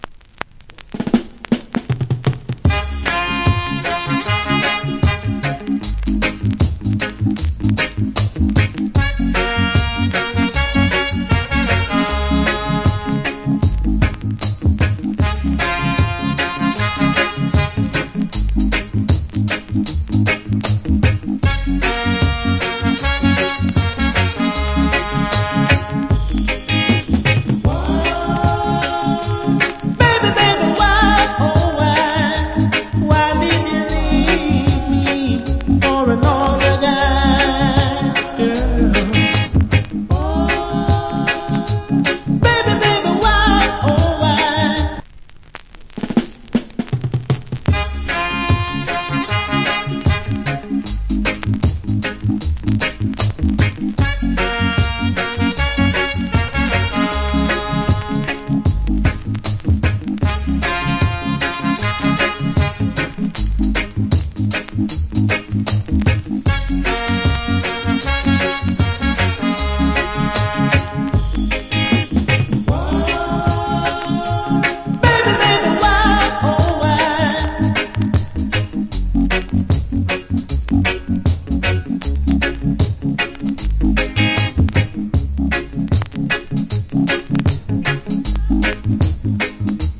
Nice Reggae Vocal. Medley.